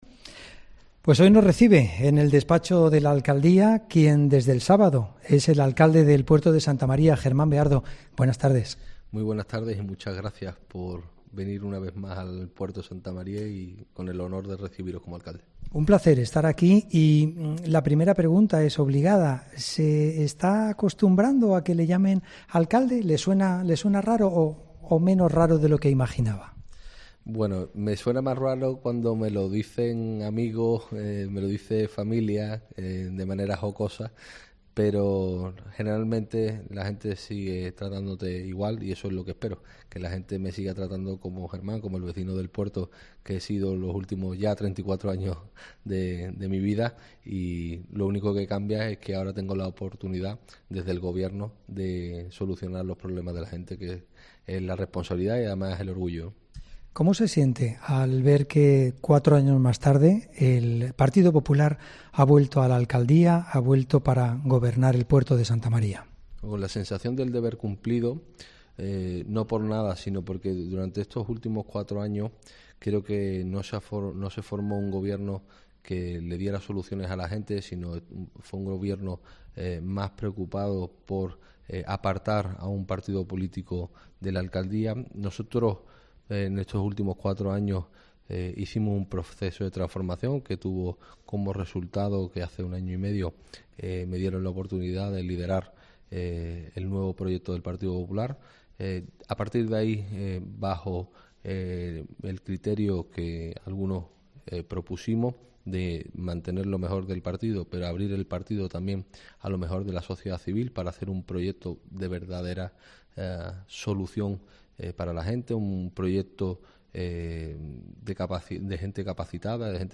Germán Beardo ha concedido a la Cadena Cope su primera entrevista a una emisora de radio
Entrevista Germán Beardo, nuevo alcalde de El Puerto de Santa María